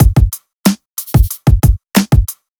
FK092BEAT1-L.wav